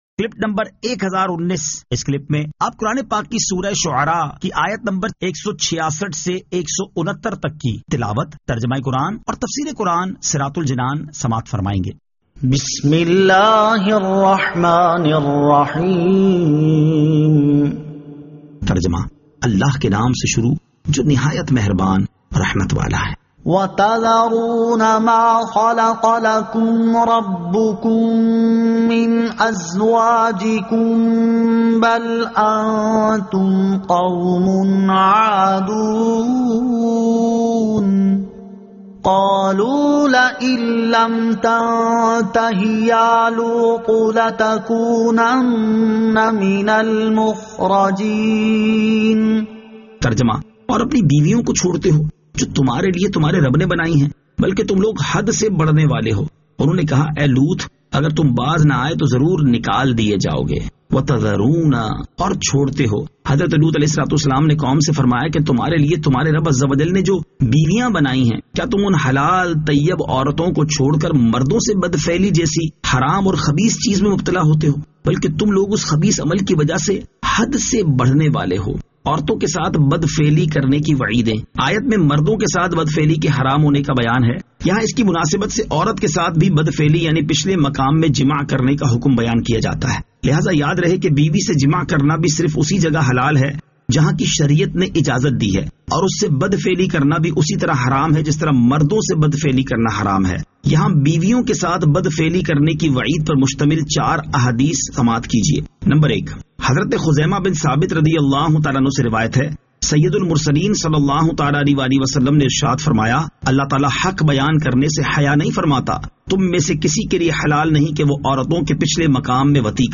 Surah Ash-Shu'ara 166 To 169 Tilawat , Tarjama , Tafseer